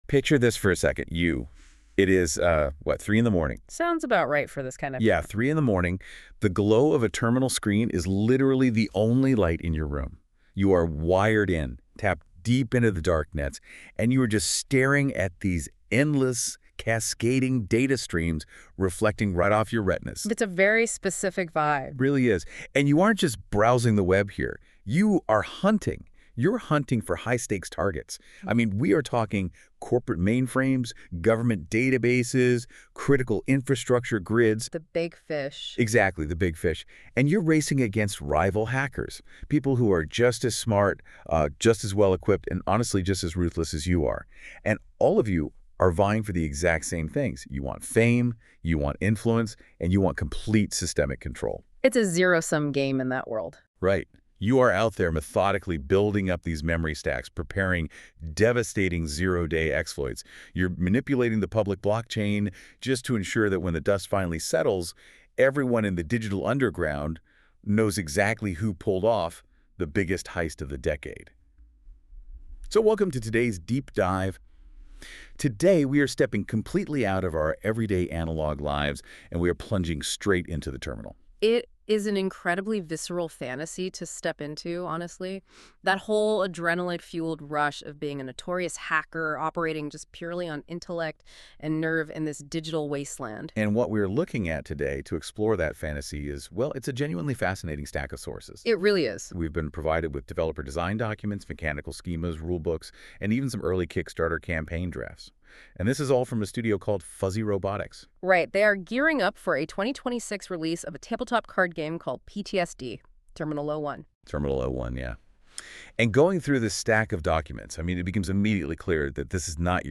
NotebookLM overview: PTSD Terminal 01 the open source hacking game